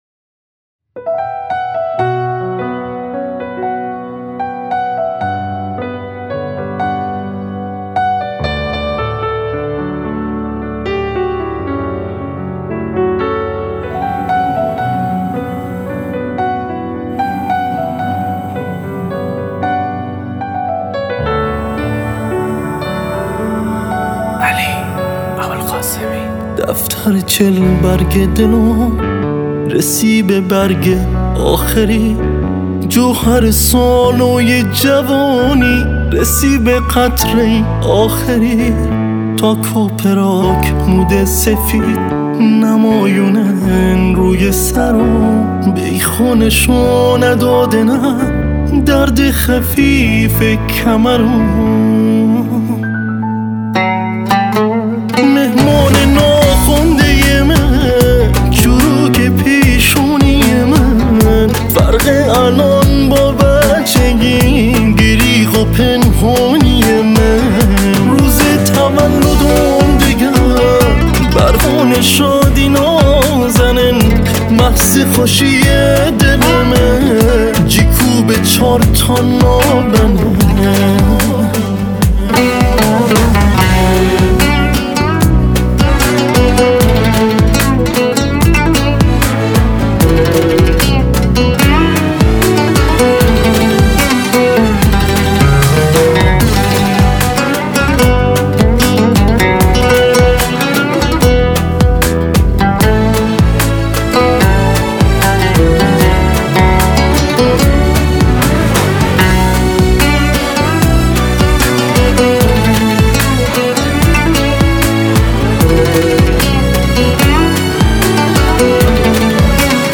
🎸 عود